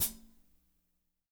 -16  HAT22-L.wav